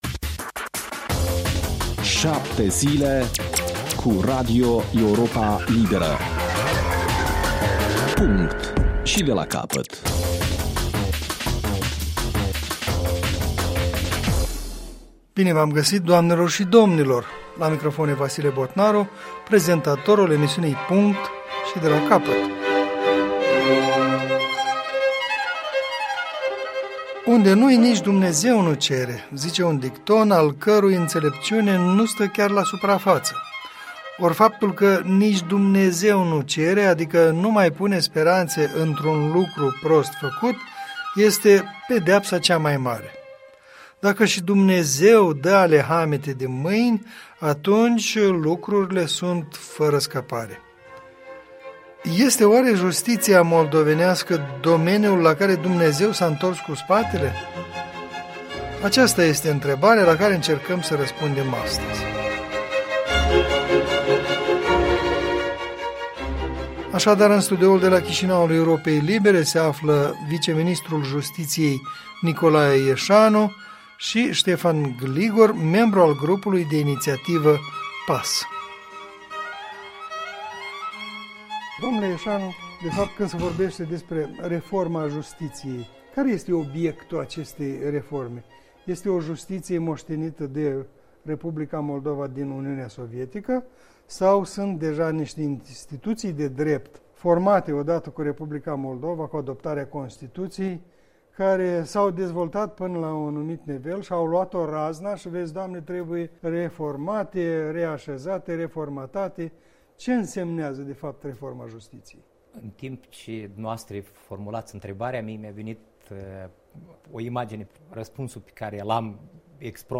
Punct și de la capăt. O dezbatere